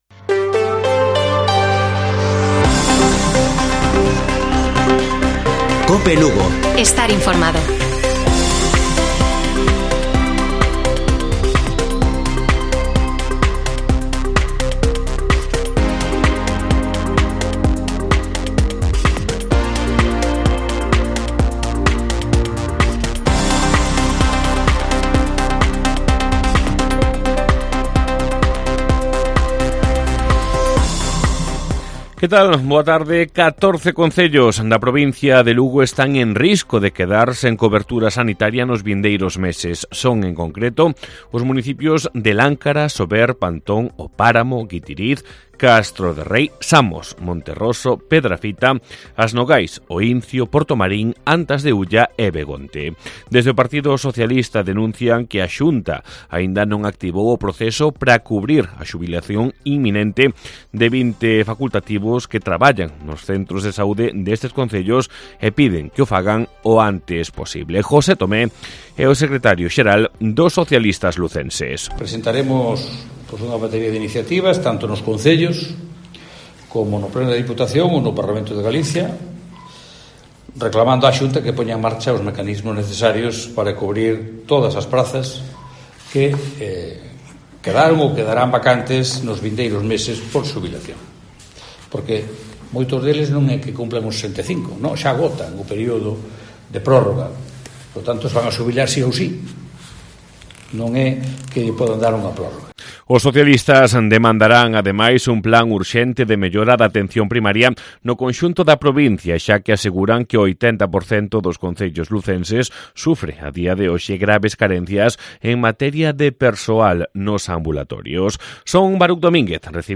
Informativo Mediodía de Cope Lugo. 29 de agosto. 14:20 horas